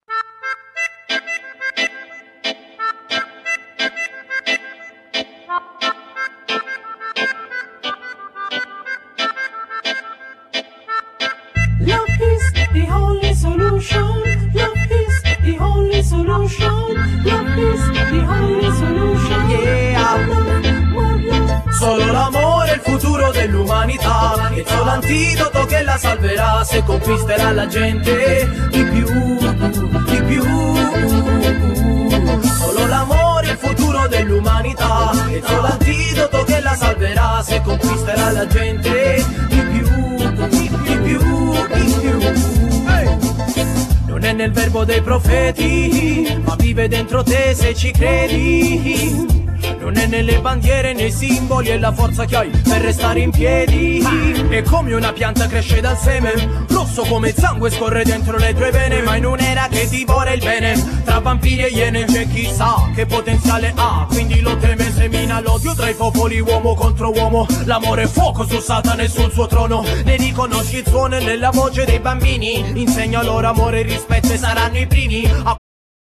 Genere : Raggae Hip hop